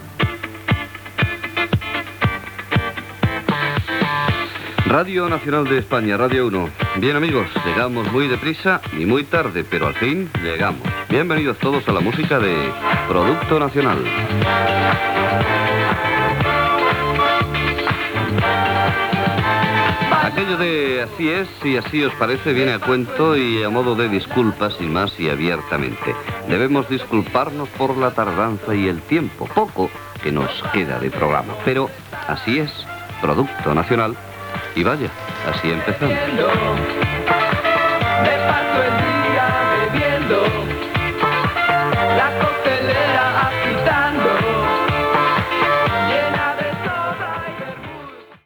Identificació de l'emissora com Radio 1, presentació inicial i tema musical.
Tema musical, comait del programa, amb els noms de l'equip, i tema musical
Musical